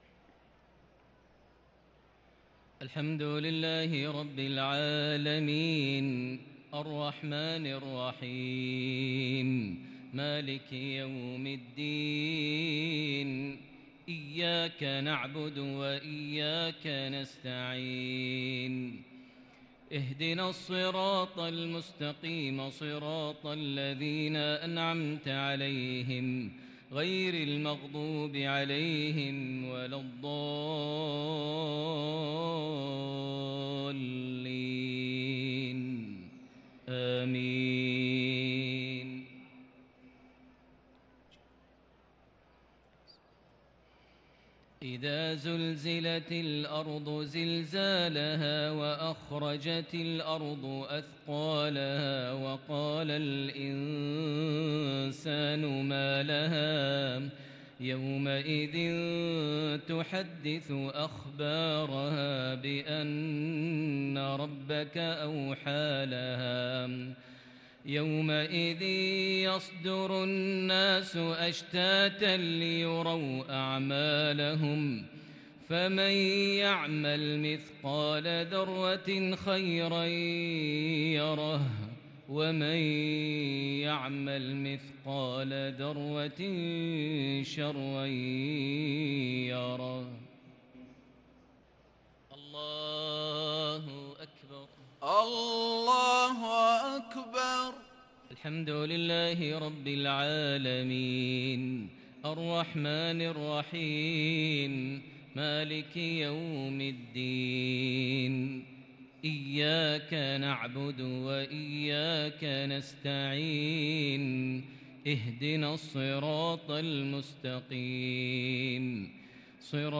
صلاة المغرب للقارئ ماهر المعيقلي 28 ربيع الآخر 1444 هـ
تِلَاوَات الْحَرَمَيْن .